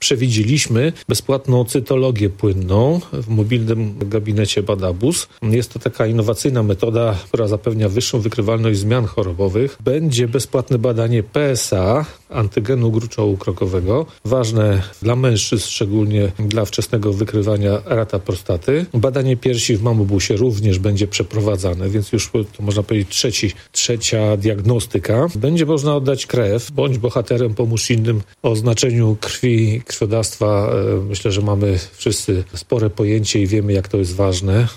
Mówił Mirosław Hołubowicz, zastępca prezydenta Ełku.